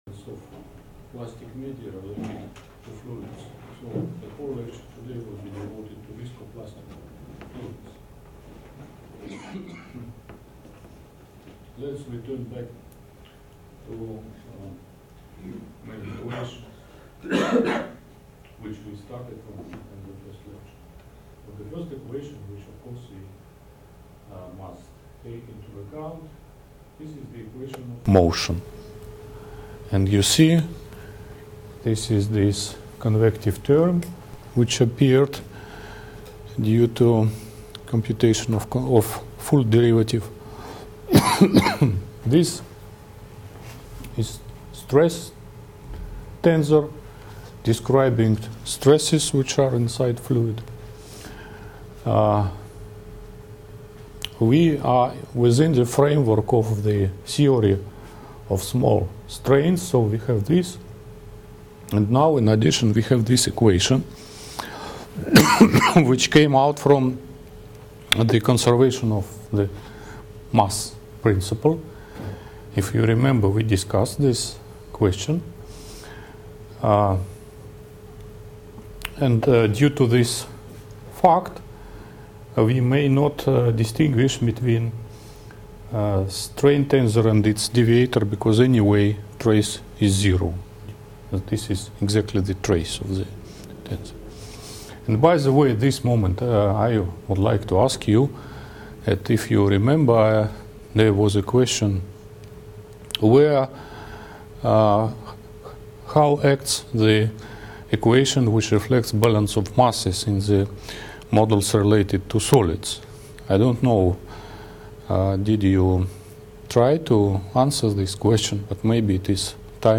lecture series on mathematical theory of plasticity